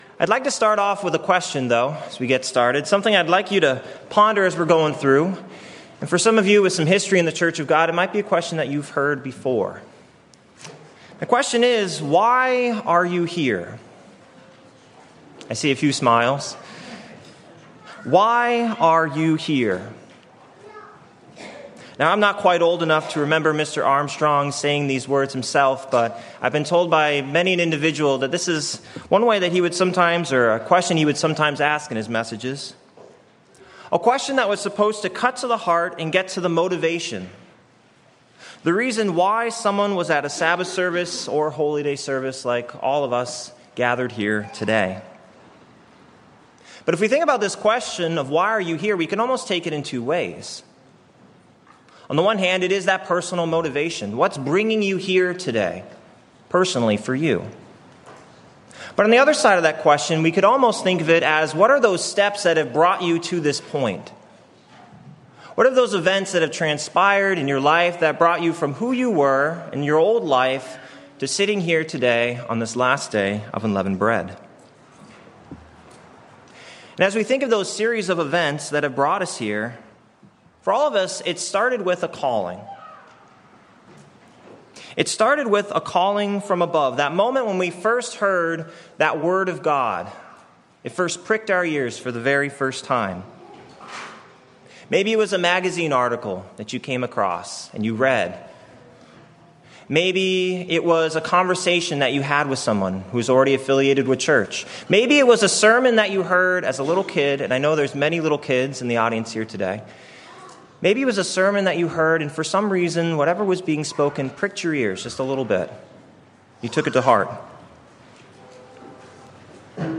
Sermons
Given in Nashville, TN